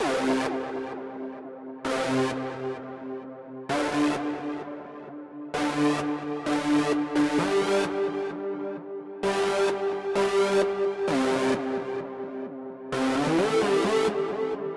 Tag: 130 bpm Dubstep Loops Synth Loops 2.49 MB wav Key : Unknown